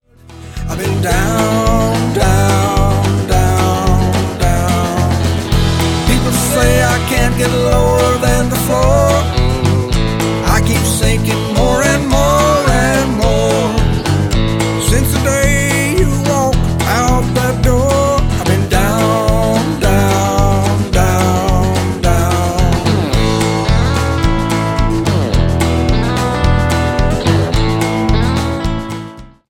I think this song harkens back to early rock and roll.